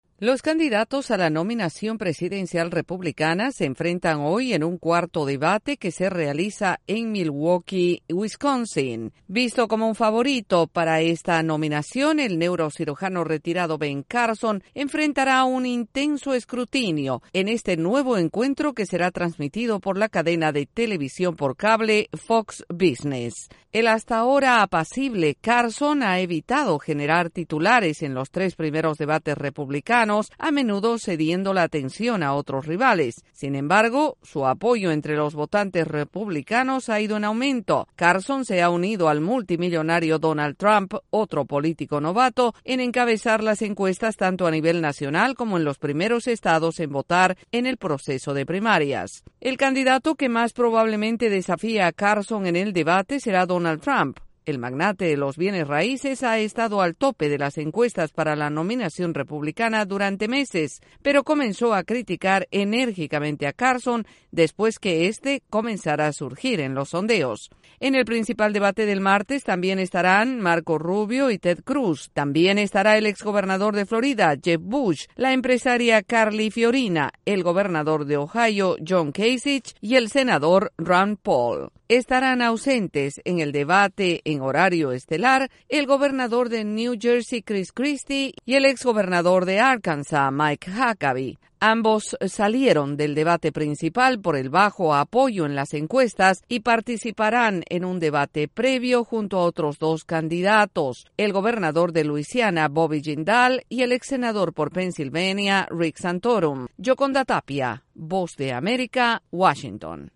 Hoy en Wisconsin se realiza el cuarto debate de los aspirantes republicanos a la presidencia de Estados Unidos. Desde la Voz de América en Washington DC informa